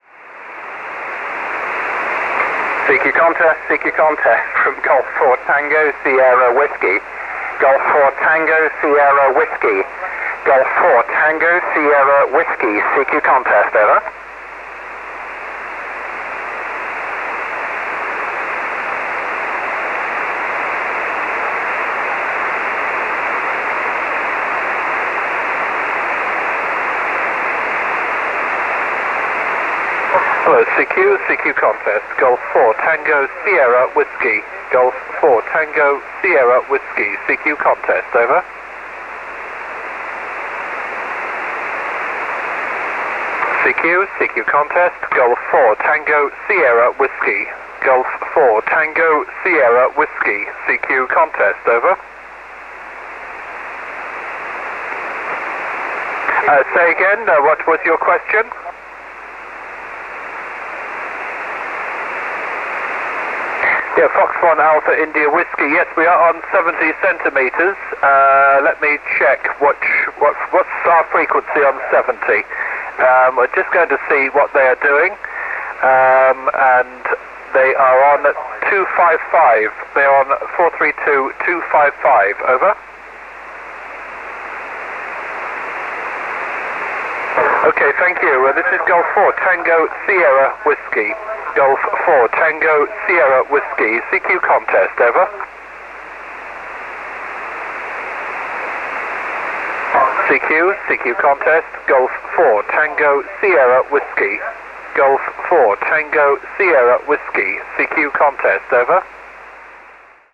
Mais en fin d’après midi, quand la température à baissé, cela a changé, les signaux ont monté et j’ai pu contacter une quinzaine de stations anglaises , locators IO92, IO93, JO02 pour ne citer que ceux-là.
Ce soir la bande 144 n’avait rien à envier aux bandes décimétriques tant il y avait du monde, un régal.